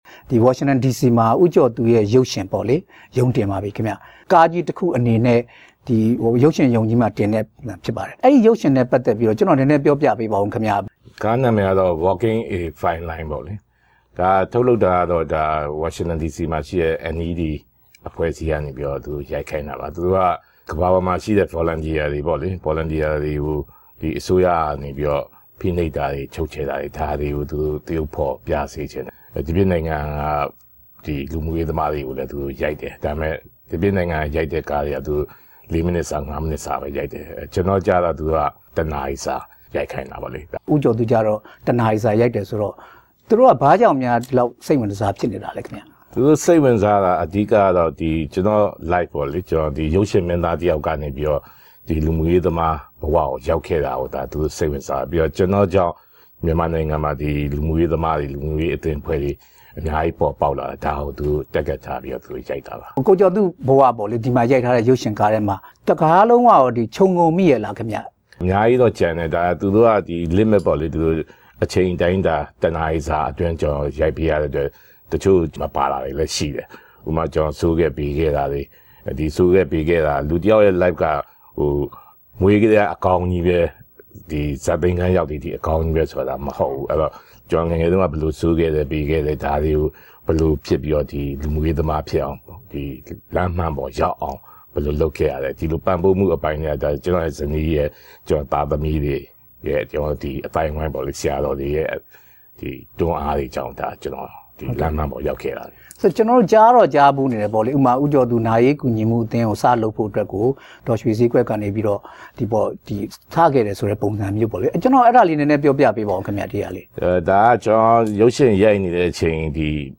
ဦးကျော်သူ နဲ့ တွေ့ဆုံမေးမြန်းချက်